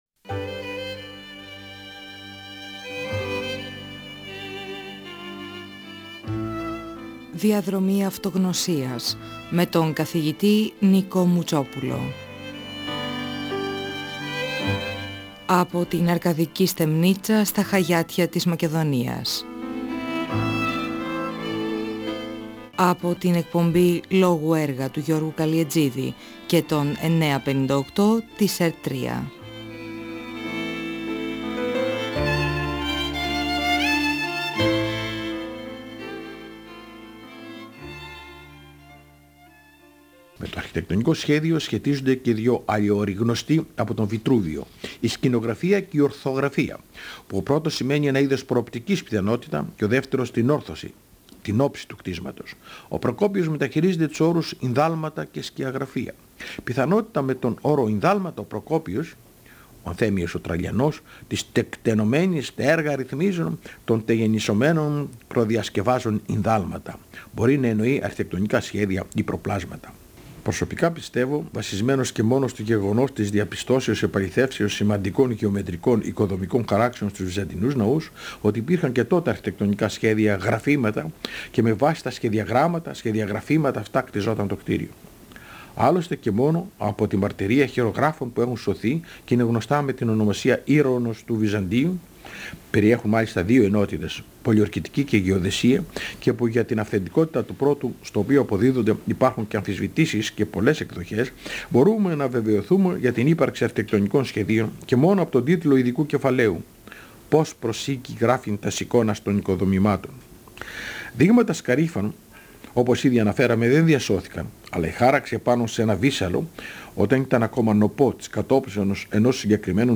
Ο αρχιτέκτονας Νικόλαος Μουτσόπουλος (1927–2019) μιλά για τον αρχιτέκτονα και την αρχιτεκτονική. Αναφέρεται στις οικοδομικές συντεχνίες και στη σύνθεσή τους την περίοδο της ρωμαϊκής αυτοκρατορίας, στην πατρίδα των φημισμένων οικοδόμων, τη χώρα τών Ισαύρων.